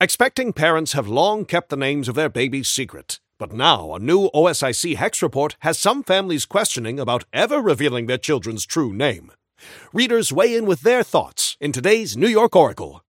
Newscaster_headline_85.mp3